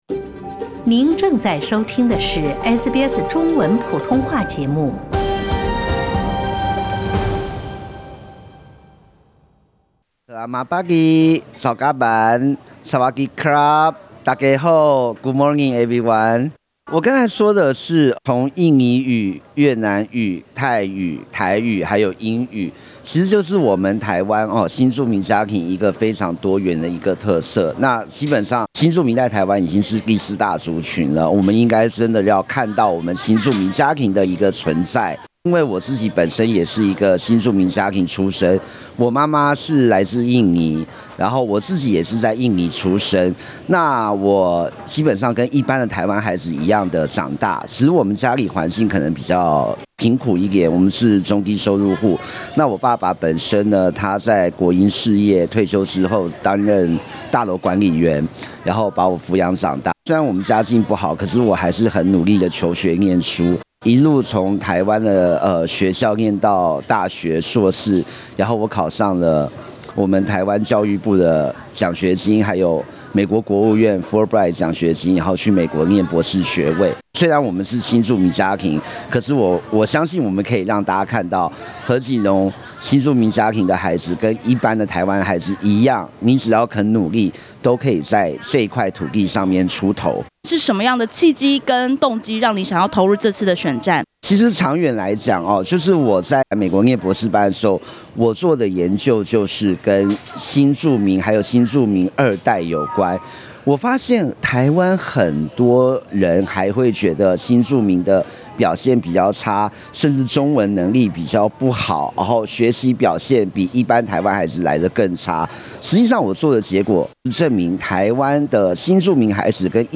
点击上方图片收听采访录音。